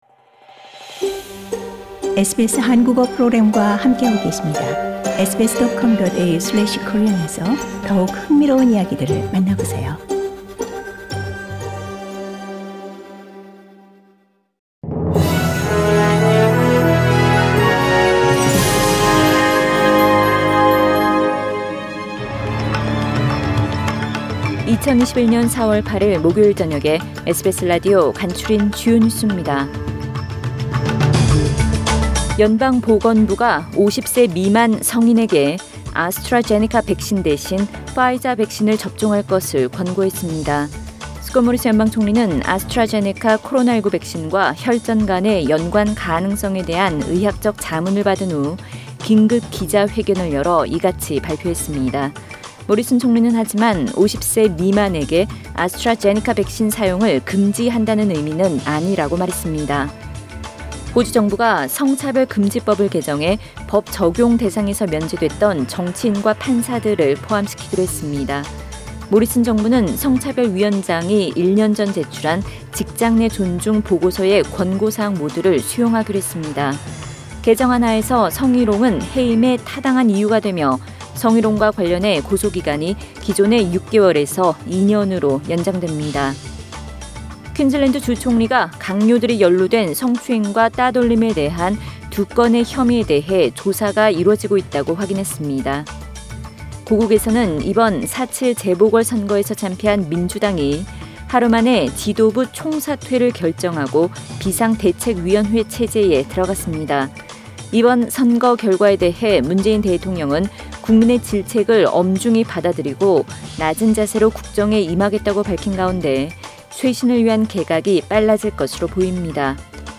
SBS News Outlines…2021년 4월 8일 저녁 주요 뉴스